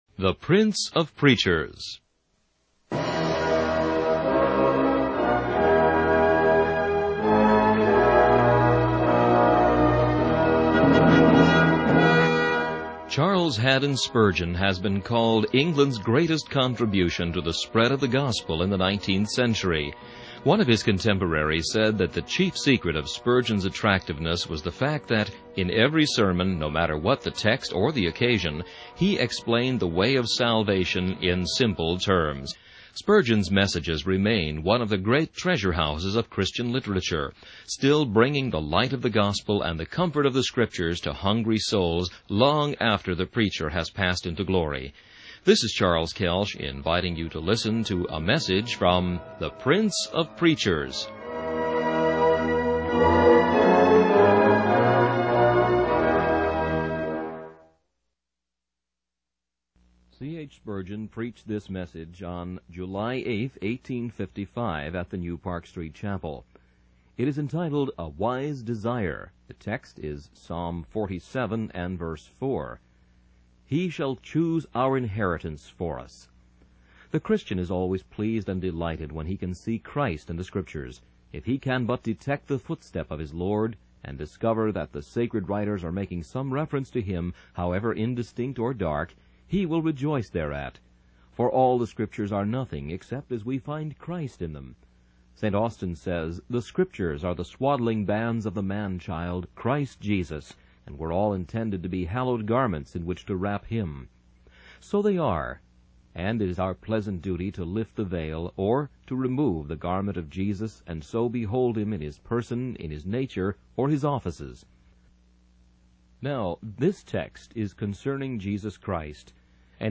Sermon of the Day The Fruits of Calvinism